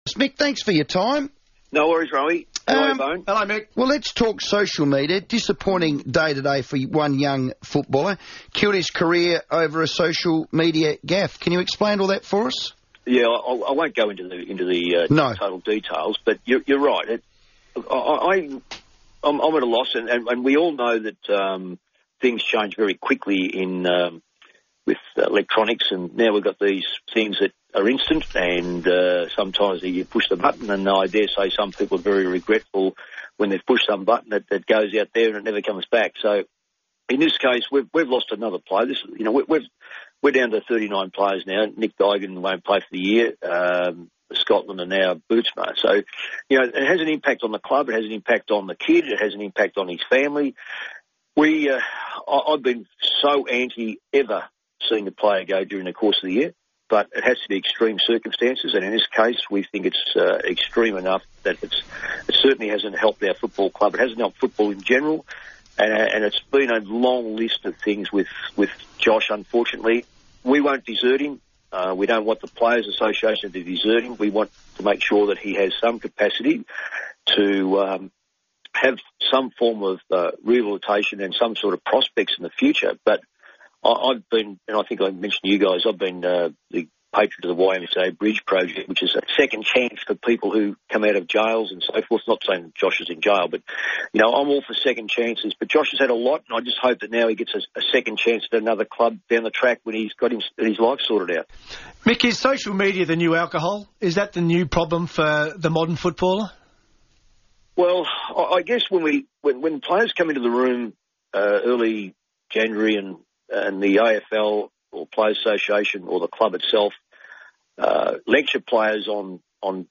Senior Coach Mick Malthouse spoke to Adelaide's FIVEaa on Tuesday, June 3.